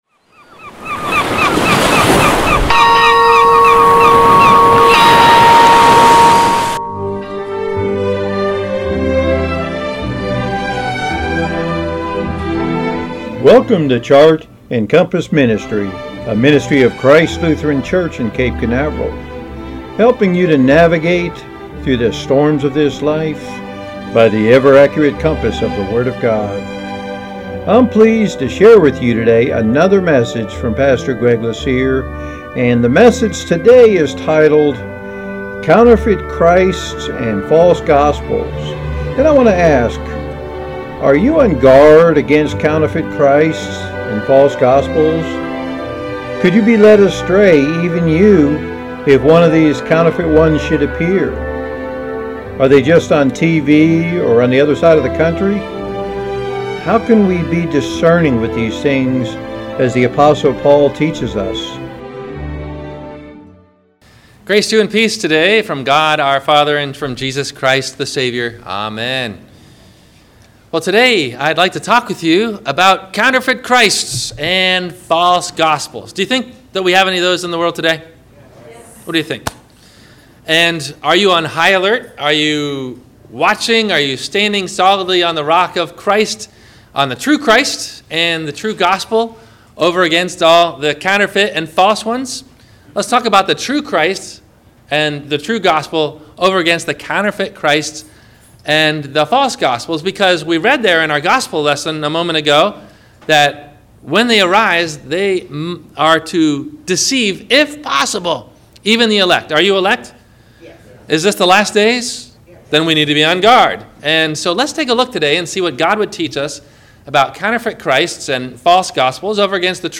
Counterfeit christ’s and false gospels – WMIE Radio Sermon – August 07 2017